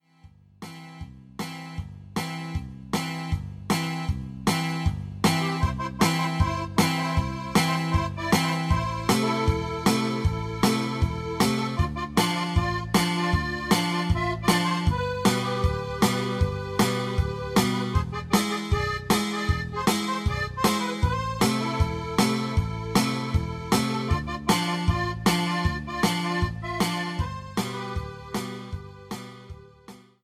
90-Norteno-3.mp3